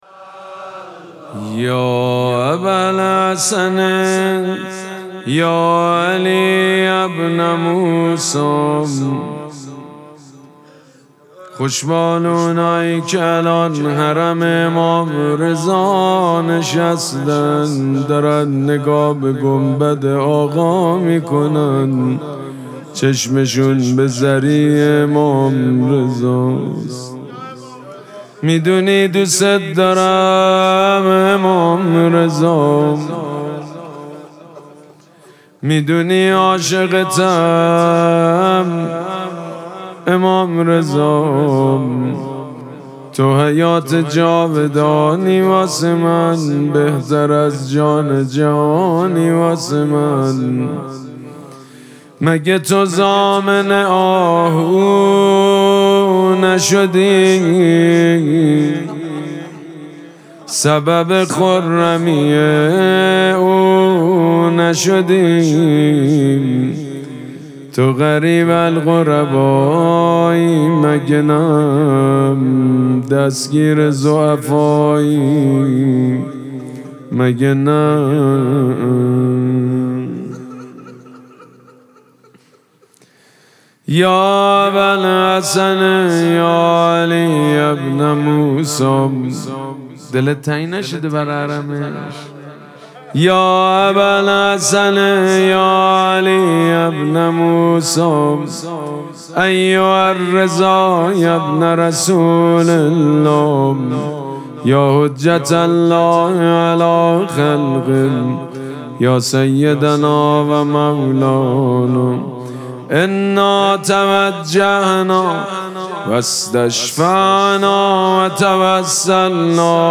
مراسم مناجات شب چهارم ماه مبارک رمضان
شعر خوانی
حاج سید مجید بنی فاطمه